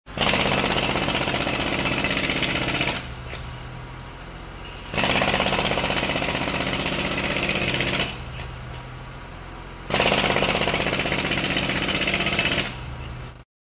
دانلود صدای هیلتی یا چکش مکانیکی 3 از ساعد نیوز با لینک مستقیم و کیفیت بالا
جلوه های صوتی